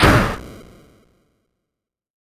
contestantExplode.ogg